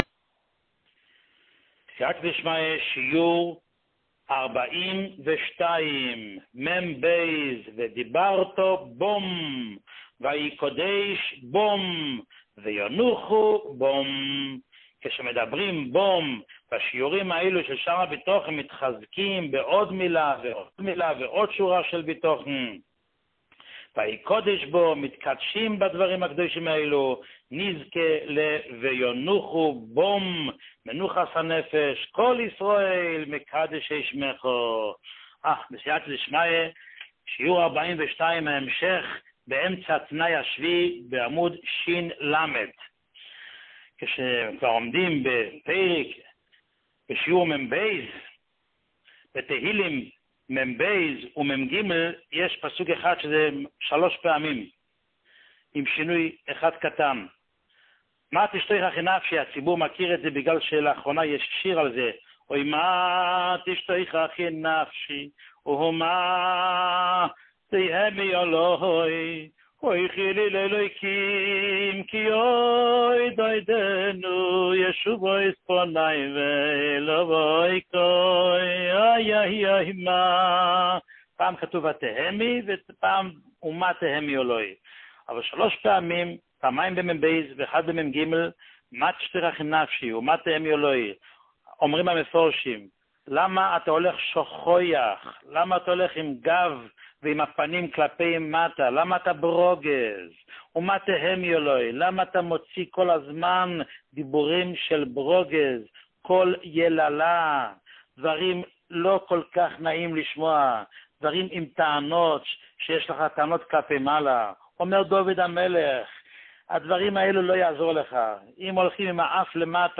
שיעורים מיוחדים
שיעור 42